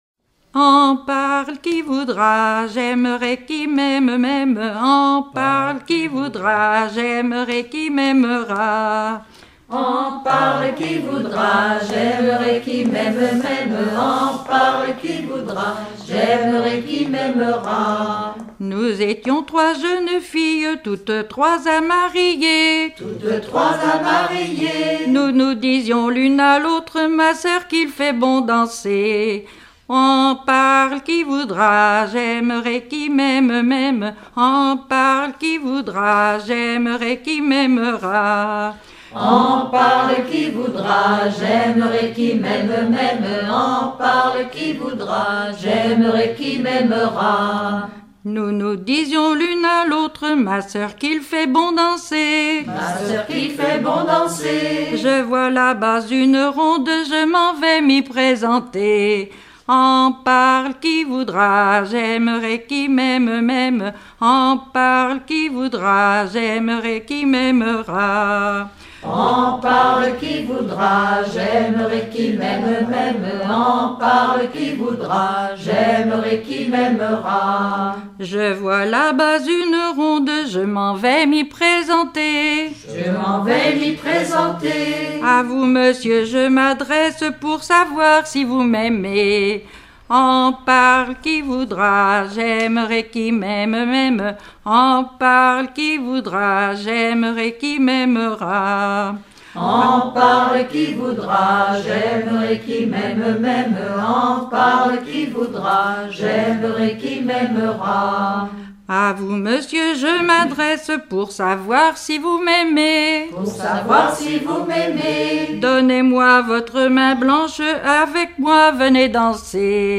Rondes enfantines à baisers ou mariages
danse : ronde à marier
Pièce musicale éditée